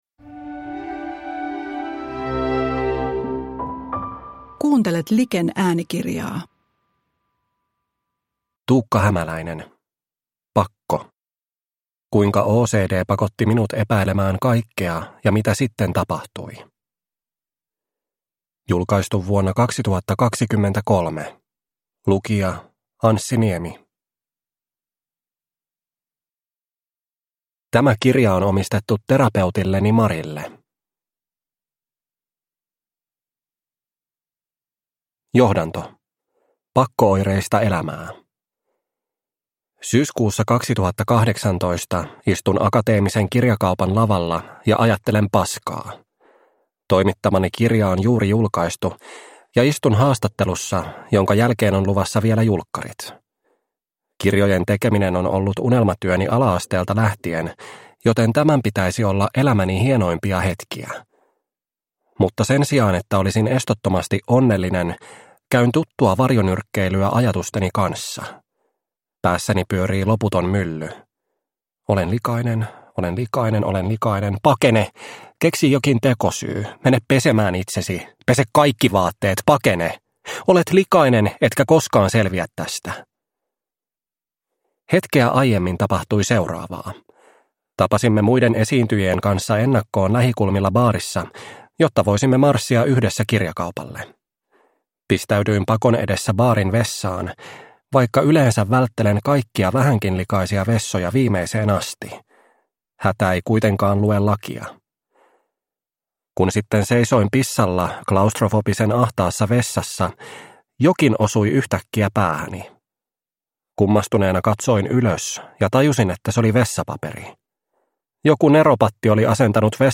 Pakko – Ljudbok – Laddas ner